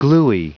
Prononciation du mot gluey en anglais (fichier audio)
Prononciation du mot : gluey